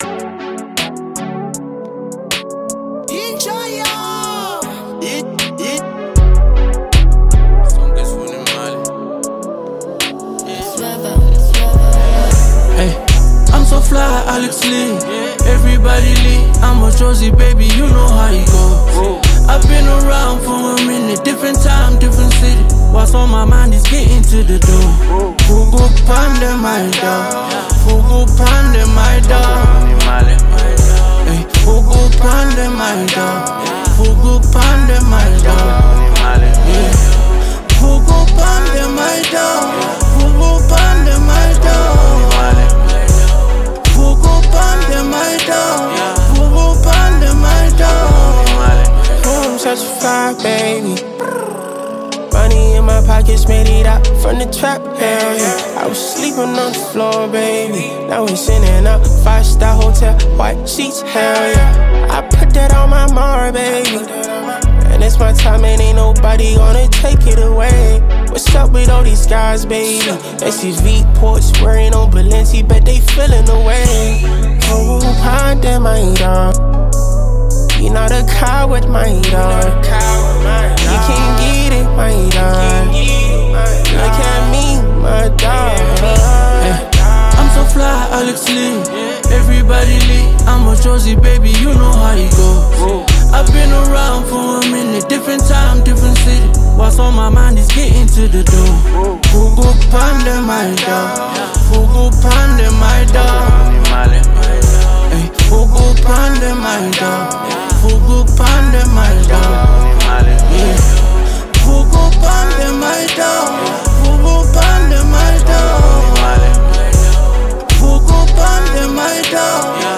Song Genre: Amapiano.